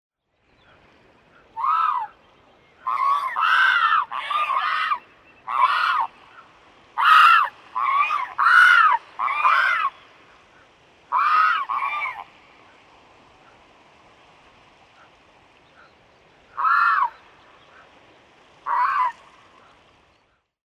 В коллекции два натуральных аудиофрагмента с голосами этих удивительных птиц.
Американский бурый пеликан издает звук когда хочет кушать